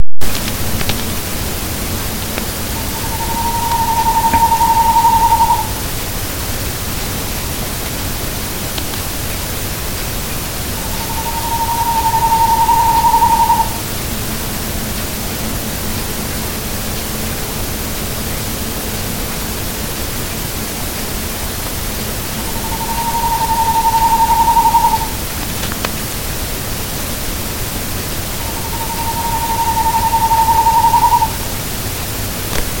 серая неясыть, Strix aluco
Administratīvā teritorijaVentspils
СтатусСлышен голос, крики